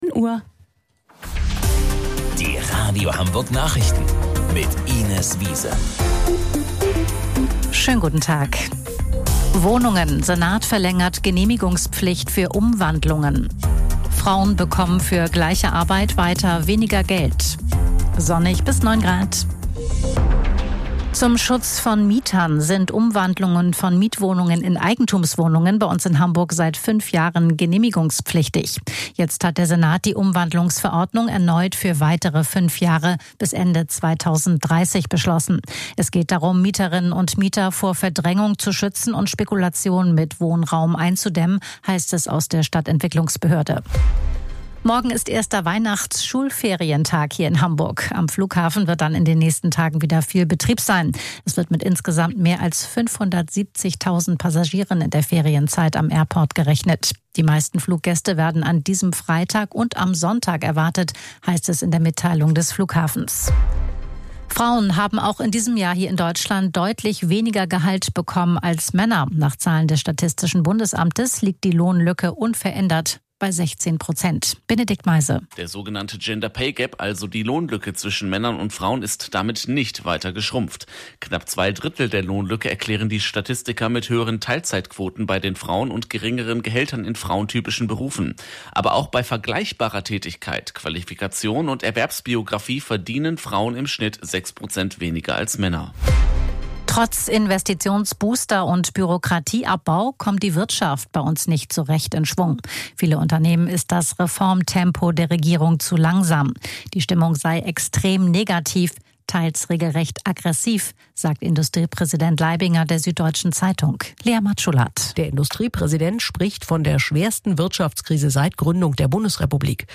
Radio Hamburg Nachrichten vom 16.12.2025 um 13 Uhr